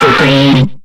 Cri de Ramboum dans Pokémon X et Y.